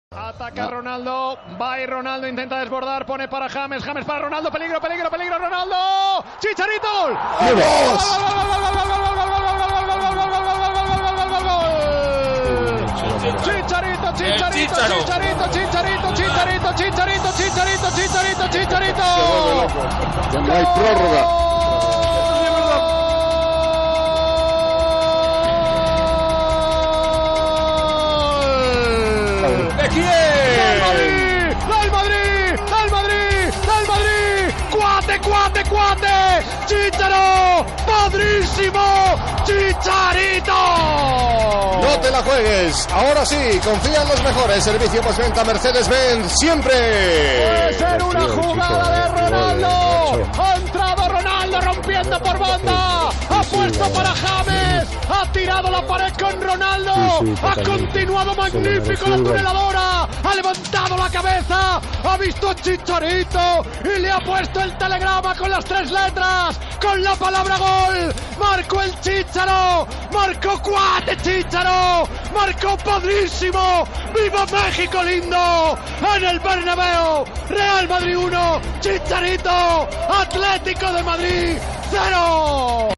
Transmissió del partit de futbol masculí de la semifinal de la Copa d'Europa de Futbol entre el Real Madrid i l'Atlético de Madrid. Narració del gol de "Chicharito" (Javier Hernández Balcázar) per al Real Madrid.
Esportiu